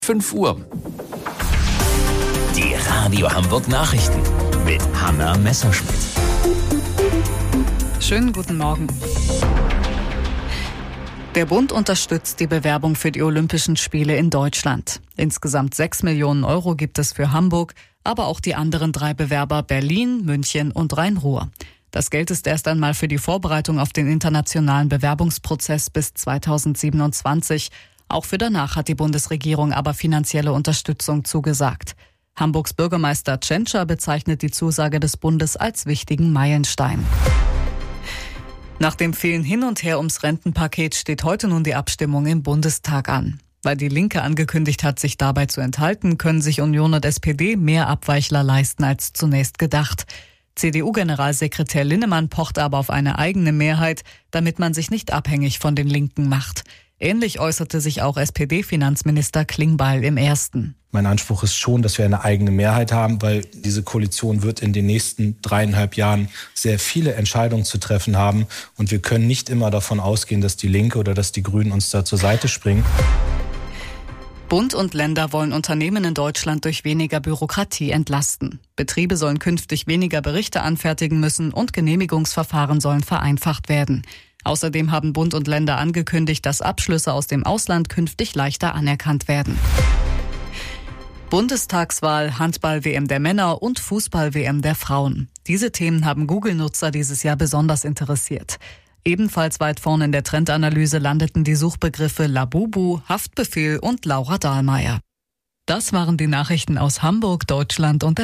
Radio Hamburg Nachrichten vom 05.12.2025 um 05 Uhr